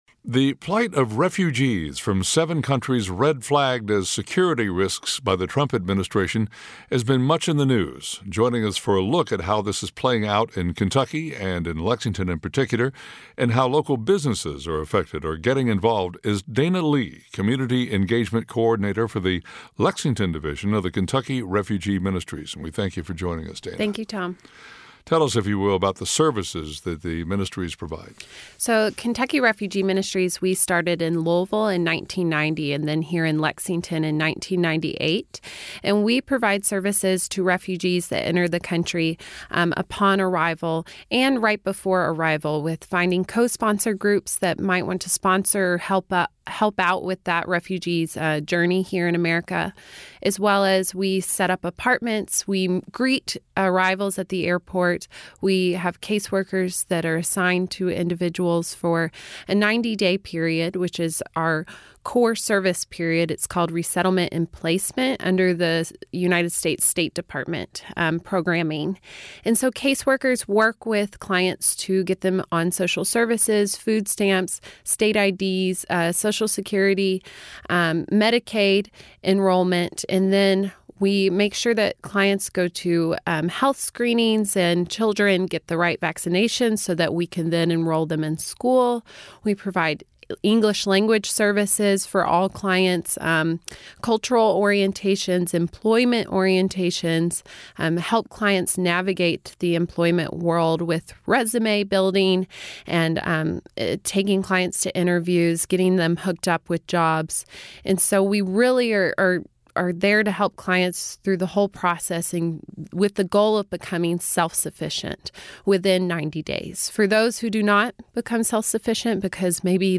Kentucky Refugee Ministries Podcast The plight of refugees from seven countries red-flagged as security risks by the Trump administration has been much in the news.